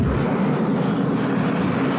Rocket Boots.wav